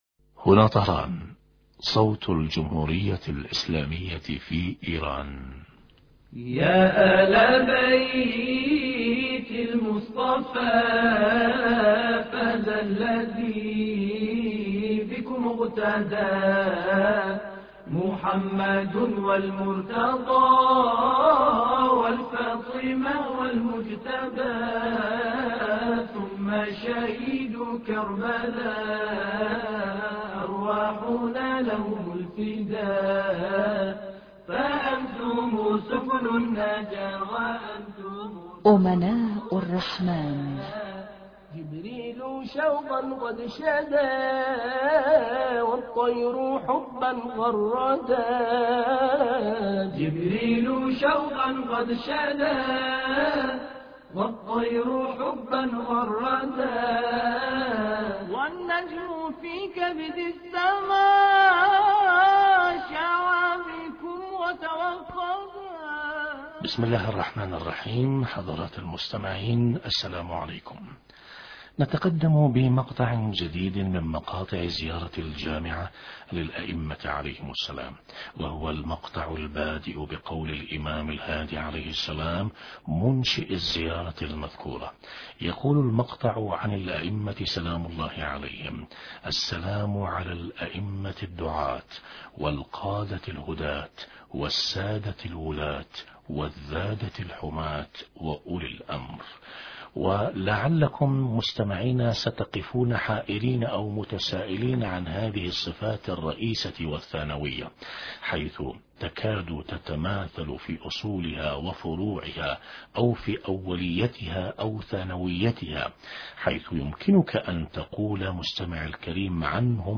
أما الآن نتابع تقديم برنامج امناء الرحمن بهذا الاتصال الهاتفي